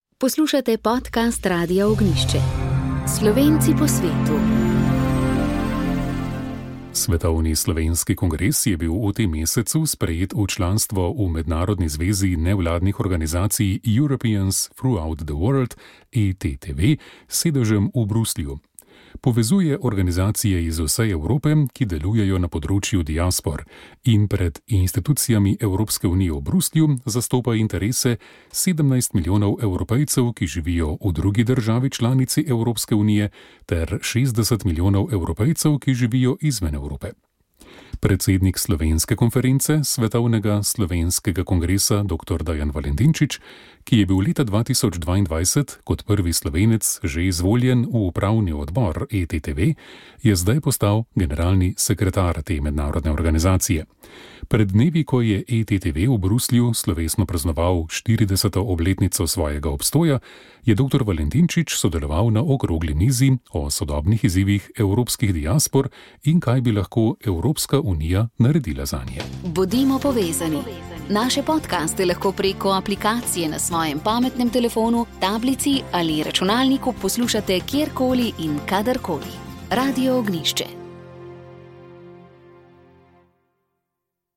Sv. maša iz stolne cerkve sv. Janeza Krstnika v Mariboru 19. 6.
Ob 10 letnici škofovske službe in ob jubileju, 70. rojstnem dnevu nadškofa ordinarija Alojzija Cvikla, smo posneli sveto mašo ob 19. uri iz MB solnice.